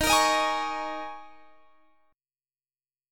D#7sus4 Chord
Listen to D#7sus4 strummed